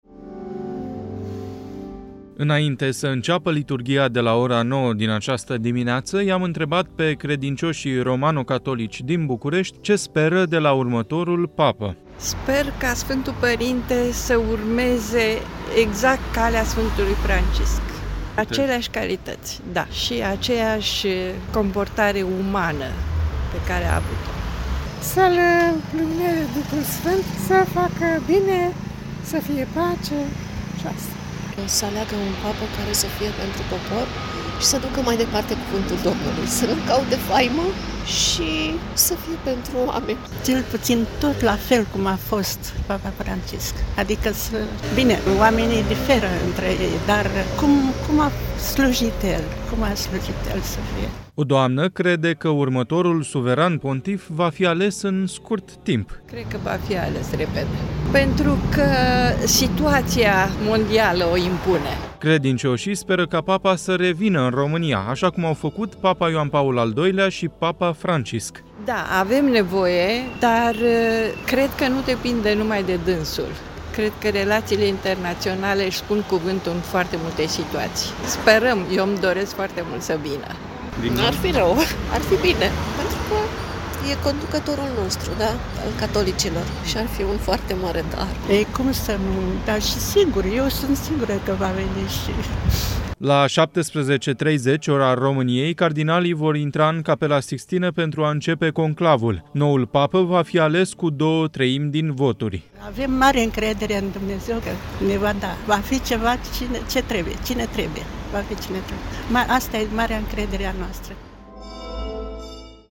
Înainte să înceapă liturghia de la ora 09:00 din această dimineață, i-am întrebat pe credincioșii romano-catolici din București ce speră de la următorul Papă:
O doamnă crede că următorul Suveran Pontif va fi ales în scurt timp: